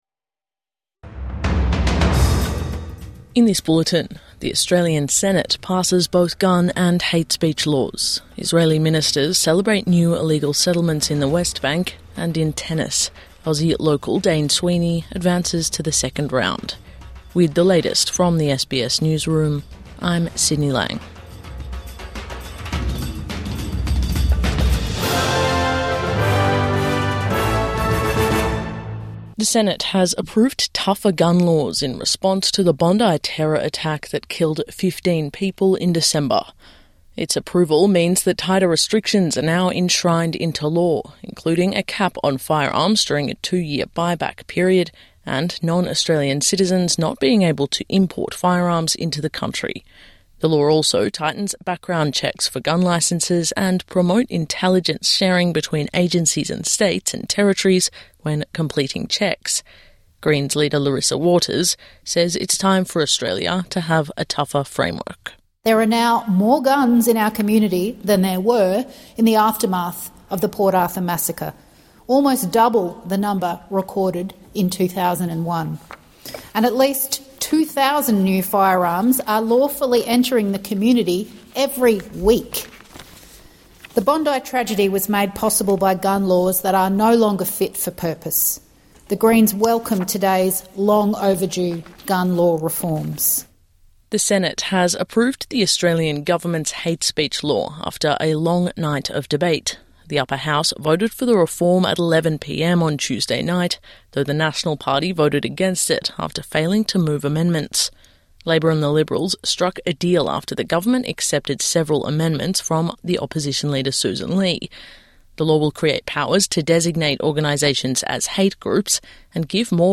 Senate approves new gun, hate speech laws | Morning News Bulletin 21 January 2026